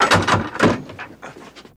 Boat Doors | Sneak On The Lot